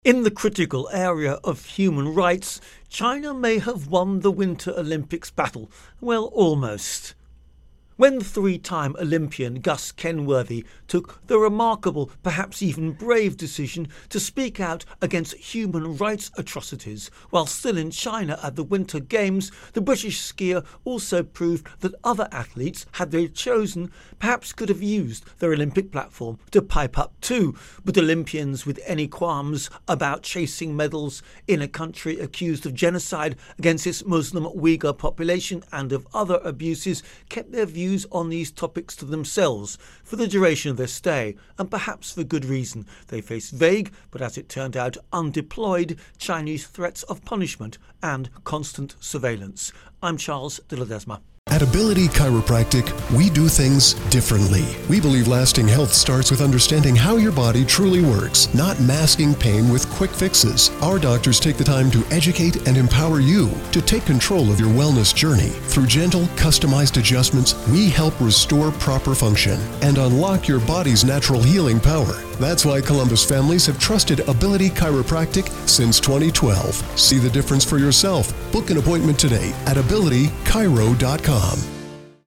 Silence of the Olympians Intro and Voicer